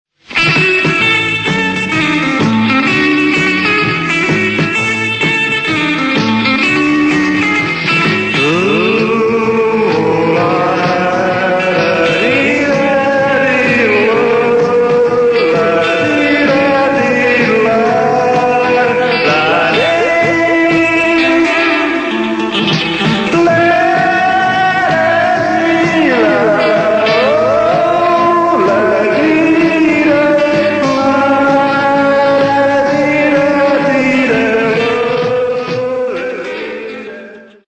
blowsy demo recording.